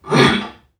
NPC_Creatures_Vocalisations_Robothead [24].wav